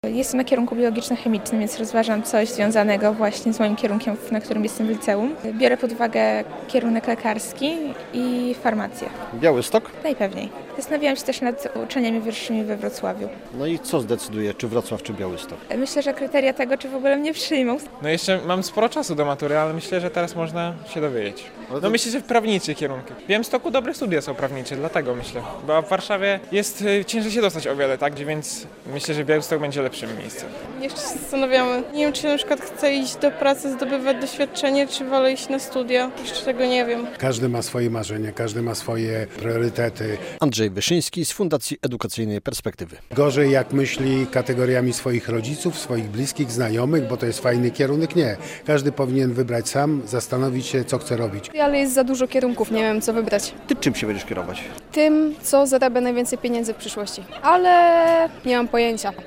Salon Maturzystów - relacja